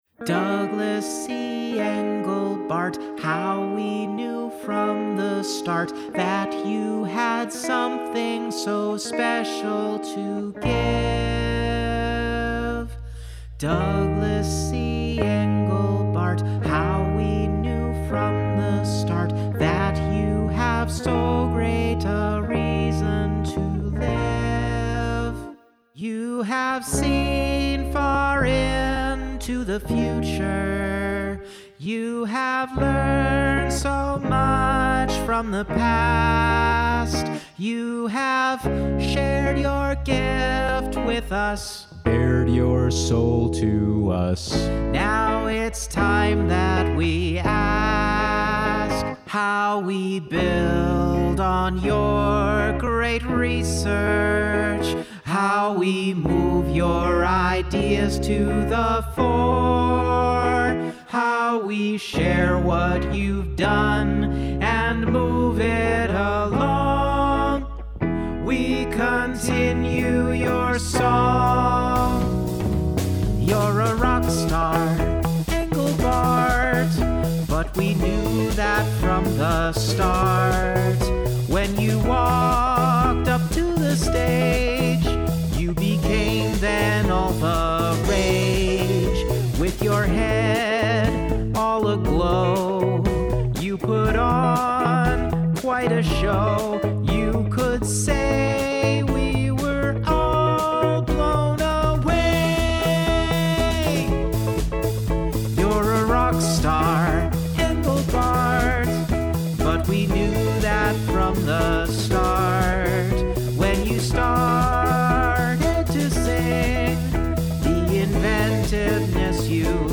(scene 5) Engelbart (duet):